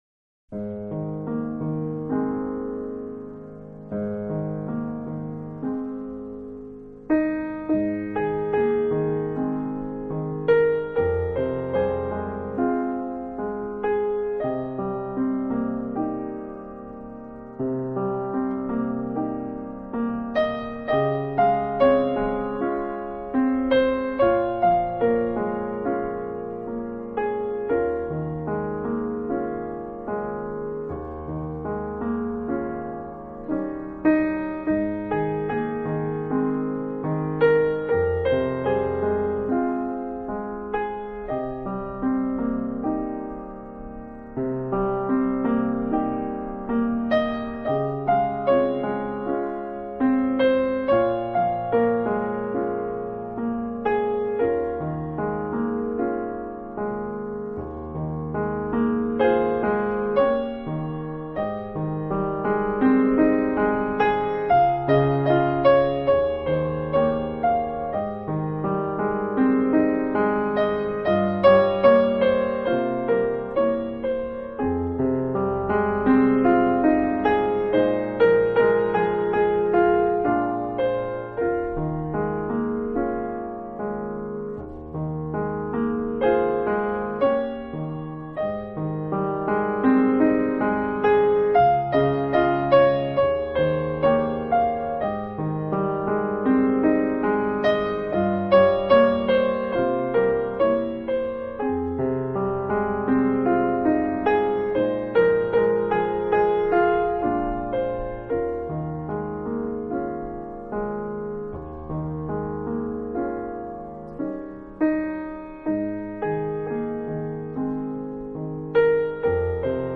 钢琴纯乐
音乐风格：New Age
雅动听的钢琴琴音更显动人。